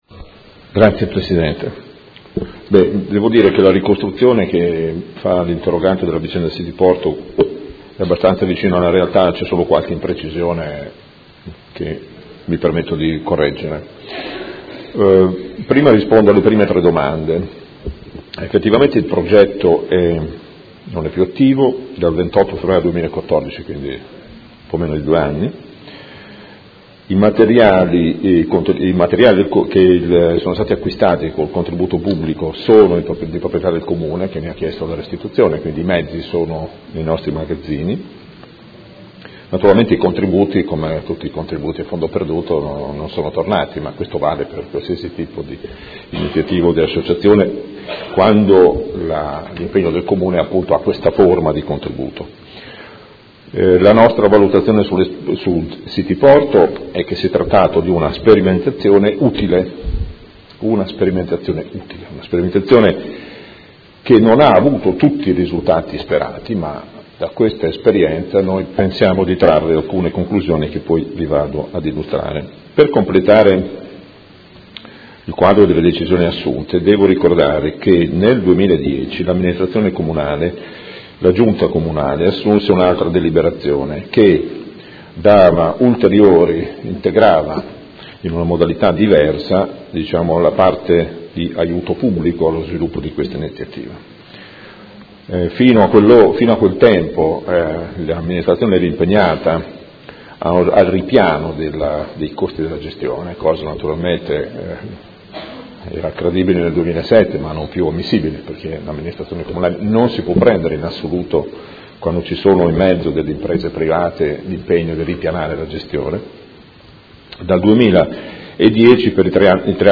Seduta del 3/12/2015. Interrogazione del Gruppo Consiliare Movimento 5 Stelle avente per oggetto: Il Progetto City Porto che fine ha fatto? Risponde l'assessore